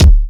WU_BD_277.wav